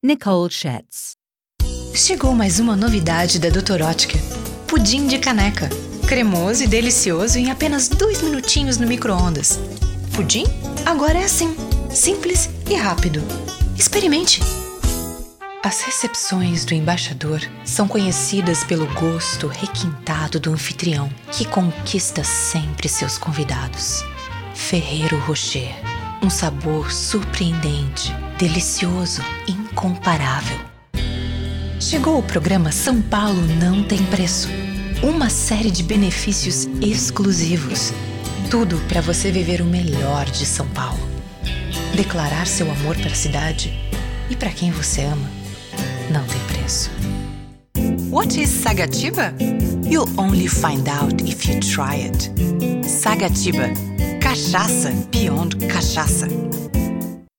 Brazilian actor and voiceover artist